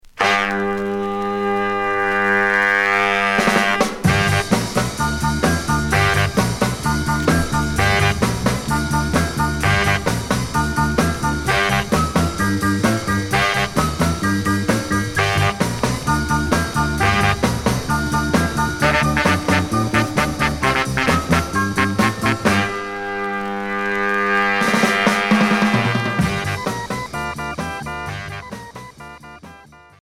Rock instrumental Premier EP retour à l'accueil